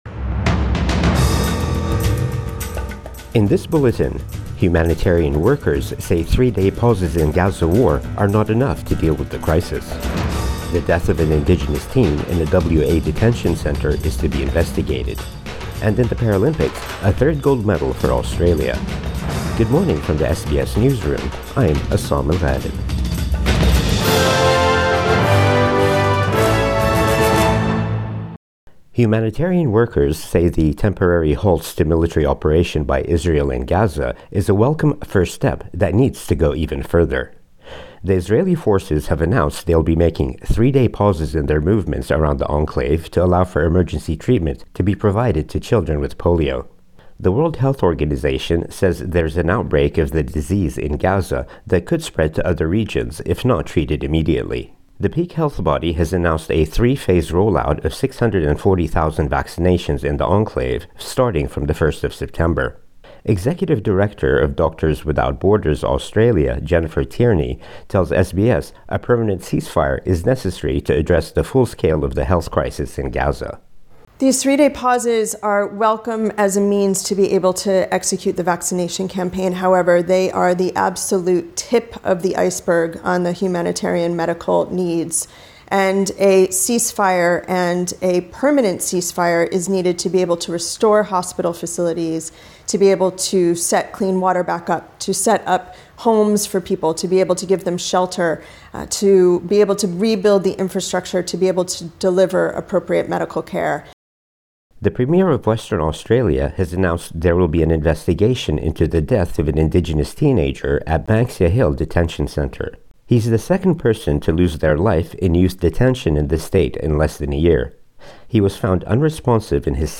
Morning News Bulletin 31 August 2024